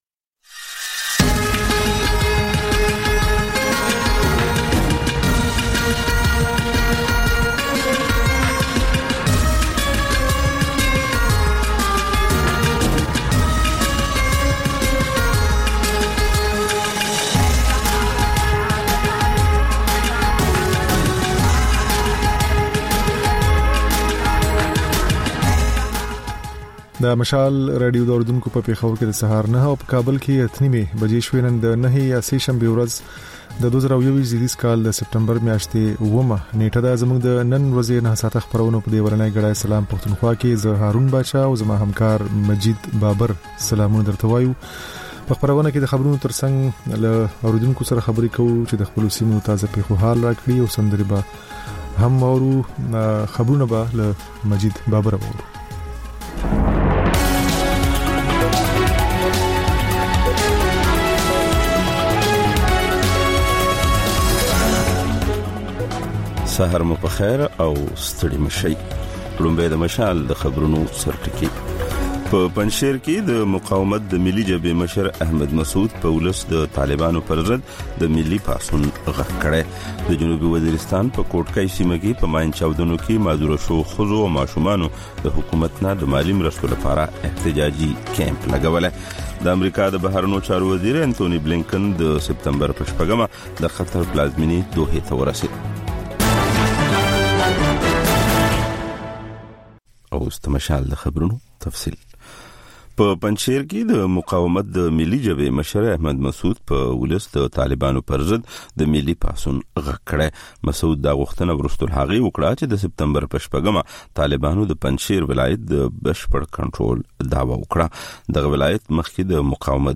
دا د مشال راډیو لومړۍ خپرونه ده چې په کې تر خبرونو وروسته رپورټونه، له خبریالانو خبرونه او رپورټونه او سندرې در خپروو.